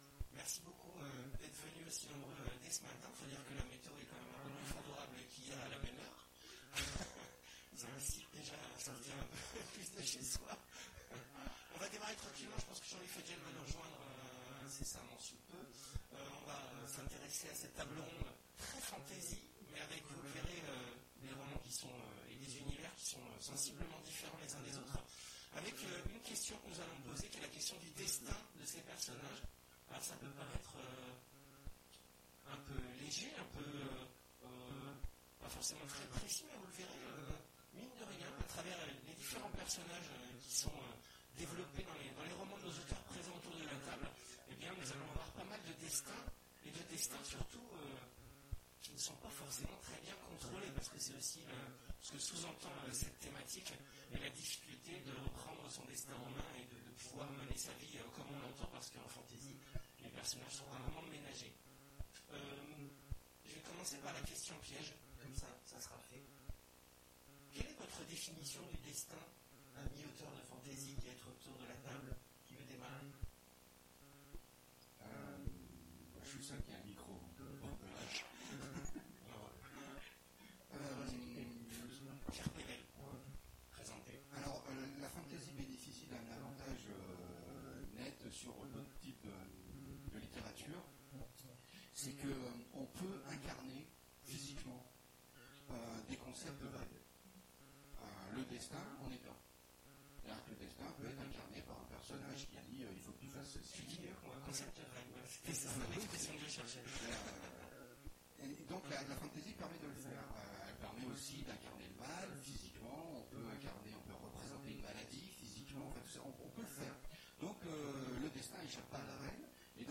Imaginales 2017 : Conférence Quand les héros de fantasy… rencontrent leur destin